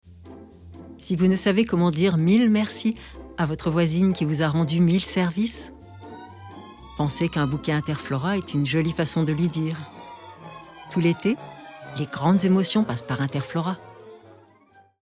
30 - 50 ans - Mezzo-soprano